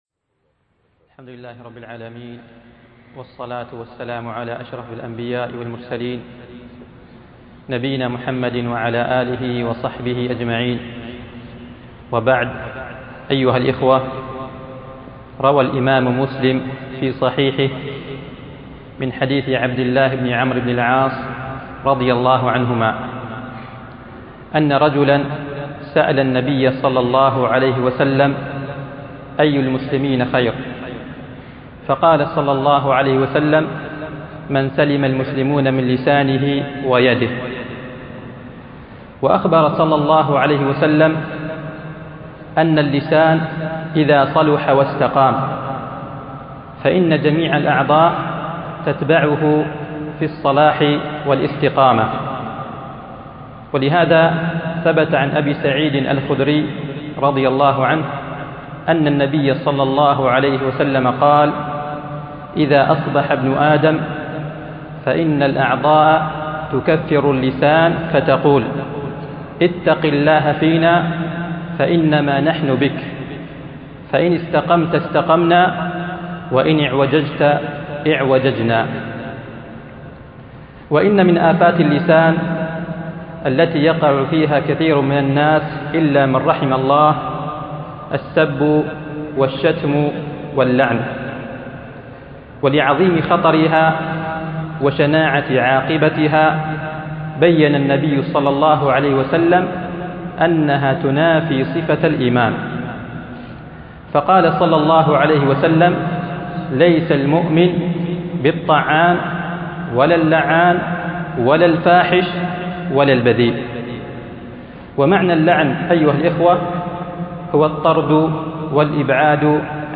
خاطرة: أخي المسلم احفظ لسانك